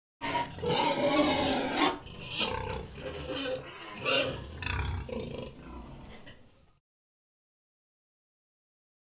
Index of /90_sSampleCDs/E-MU Producer Series Vol. 3 – Hollywood Sound Effects/Water/Pigs
PIGSTY-L.wav